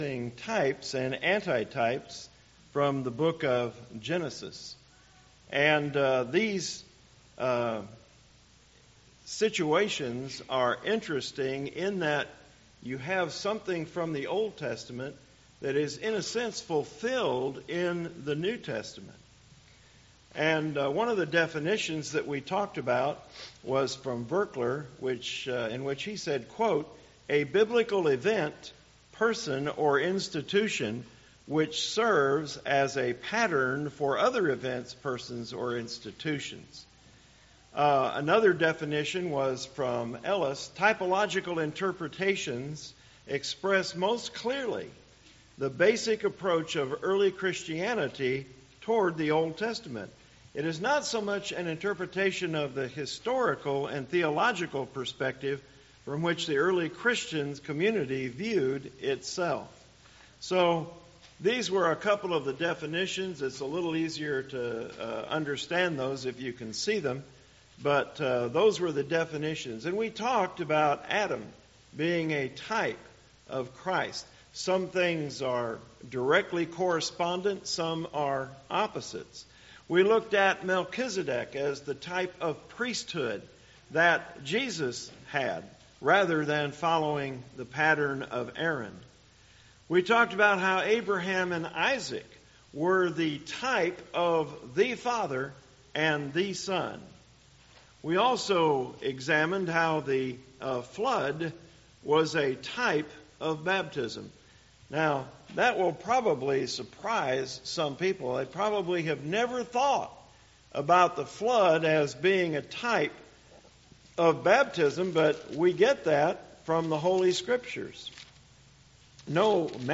Recorded audio gospel sermons from the pulpit of South Seminole church of Christ.